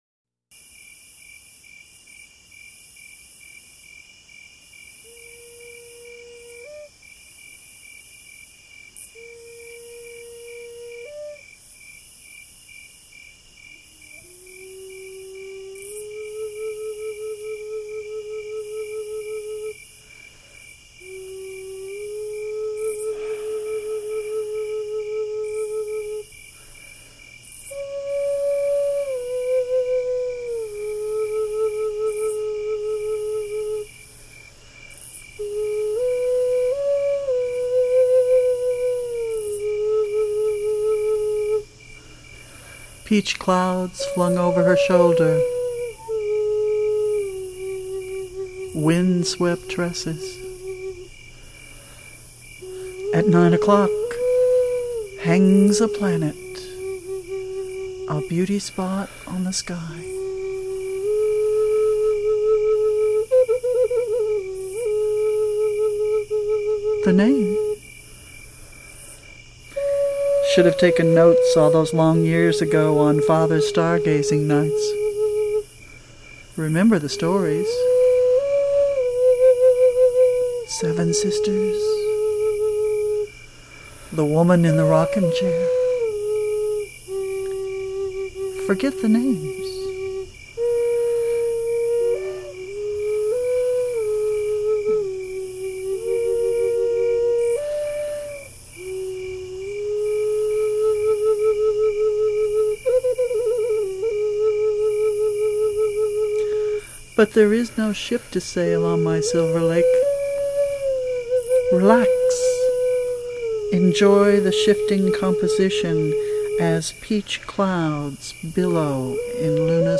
native american flute and drumming
maybe it's all the crickets in the background...